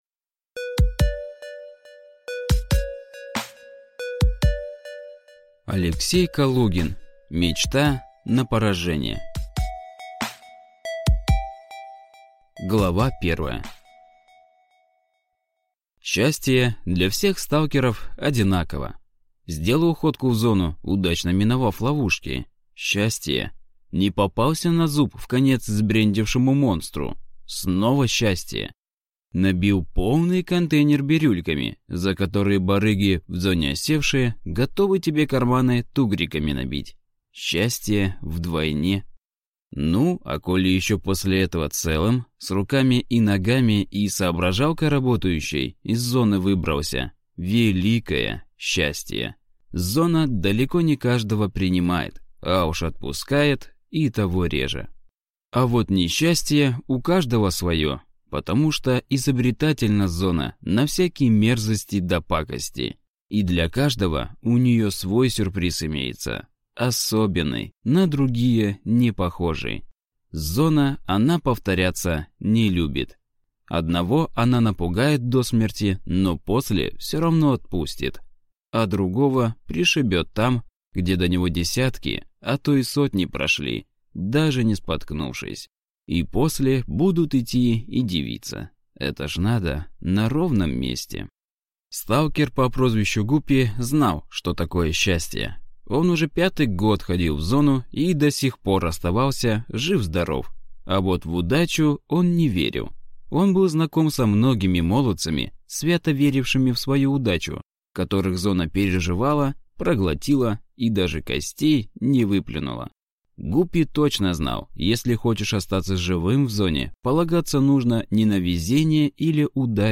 Аудиокнига Мечта на поражение | Библиотека аудиокниг
Прослушать и бесплатно скачать фрагмент аудиокниги